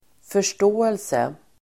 Uttal: [för_st'å:else]